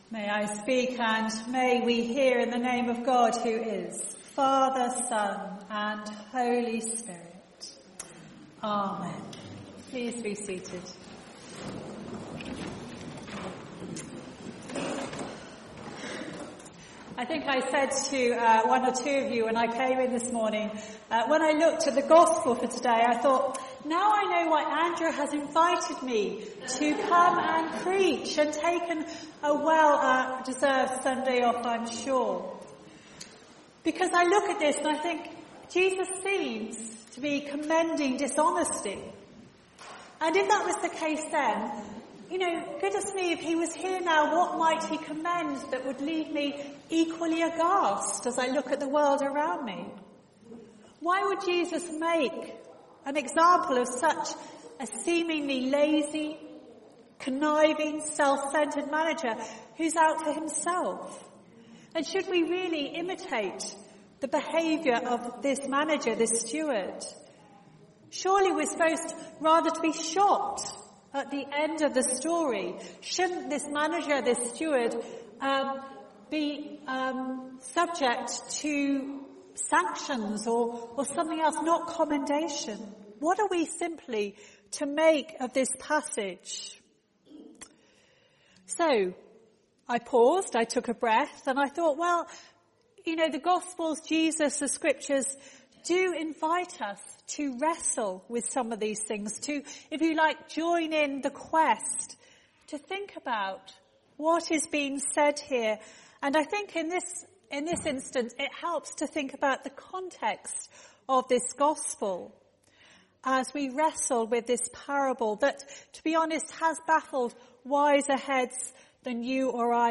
This is the Gospel of the Lord All Praise to you, O Christ Series: Ordinary Time , Sunday Morning